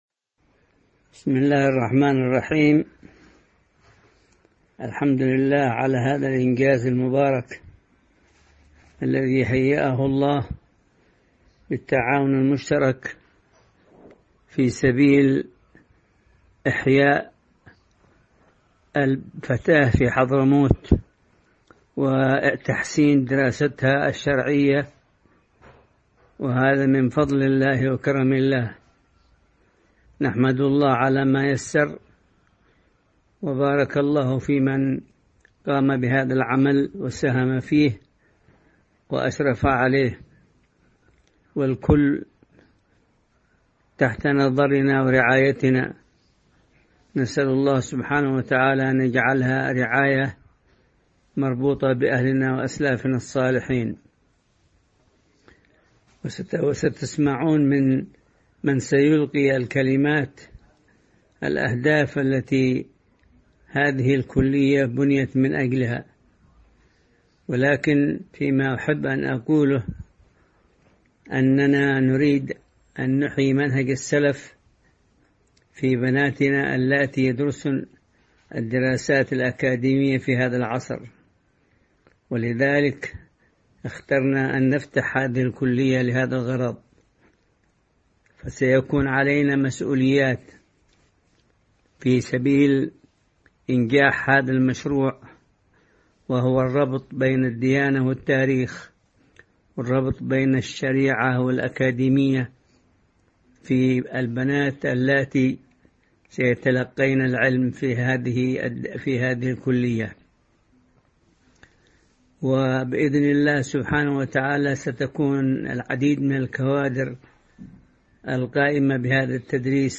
• التصنيف: كلمات عامة، للنساء